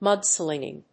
音節múd・slìnging 発音記号・読み方
/ˈmʌˌdslɪŋɪŋ(米国英語)/